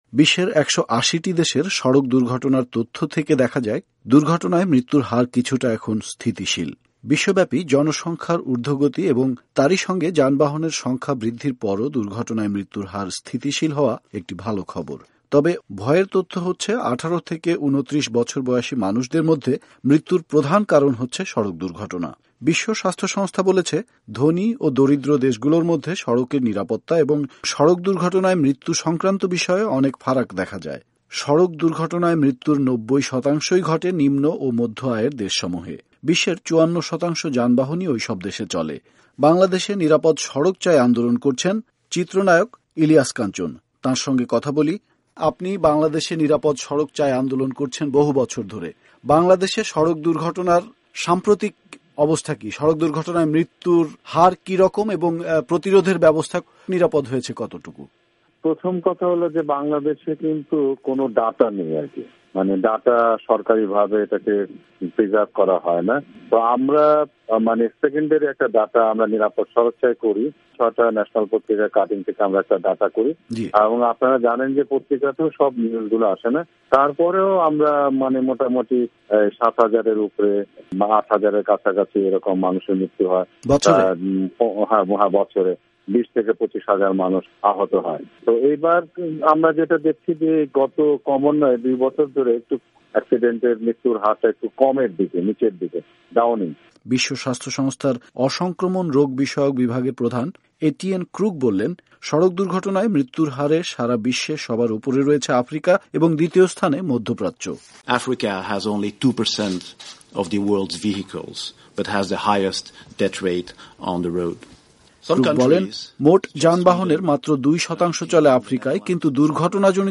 বাংলাদেশে সড়ক দুর্ঘটনা প্রতিরোধে নিরাপদ সড়ক চাই আন্দোলন করছেন চিত্রনায়ক ইলিয়াস কাঞ্চন। বিষয়টি নিয়ে আমরা তাঁর সঙ্গে কথা বলি।